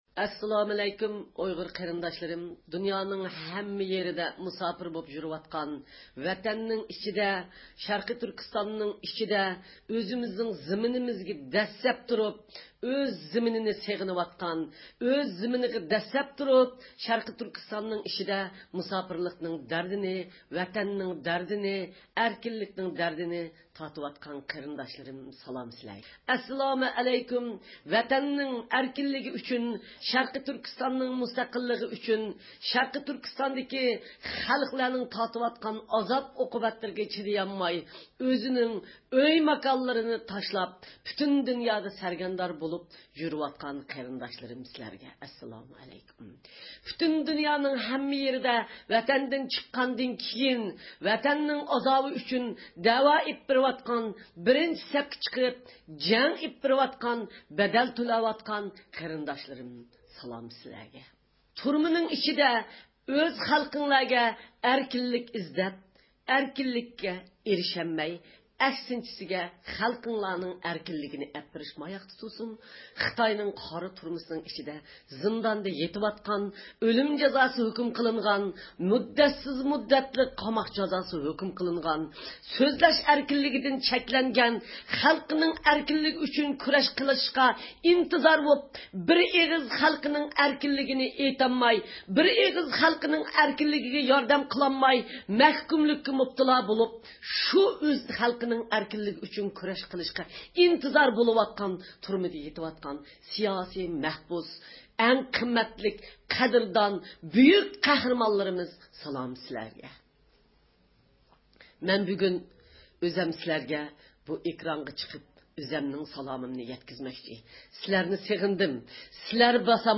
ئۇيغۇر مىللىي ھەركىتىنىڭ رەھبىرى، دۇنيا ئۇيغۇر قۇرۇلتىيىنىڭ رەئىسى رابىيە قادىر خانىم گېرمانىيىنىڭ ميۇنخېن شەھىرىدىكى زىيارىتى داۋامىدا شەرقى تۈركىستان ئىنفورماتسيۇن مەركىزى تەسىس قىلغان ئۇيغۇر تېلېۋىزىيىسىدە نوتۇق سۆزلىدى.